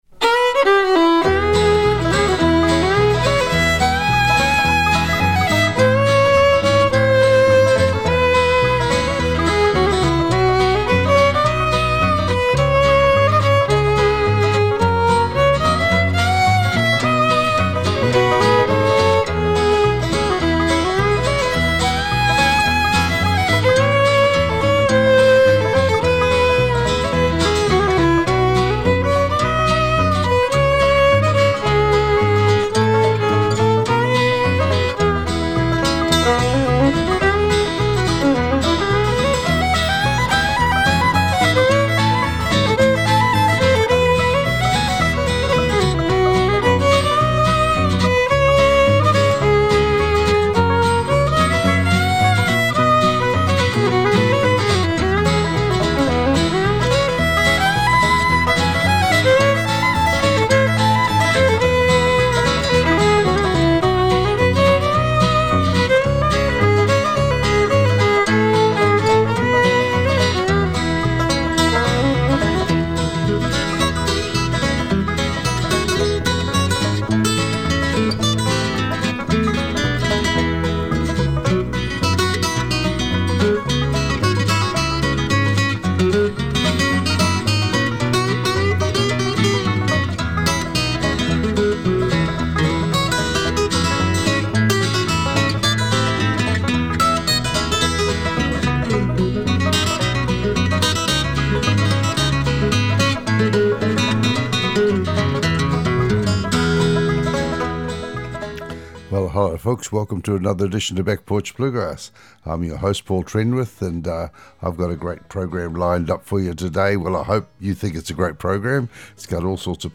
Back Porch Bluegrass Show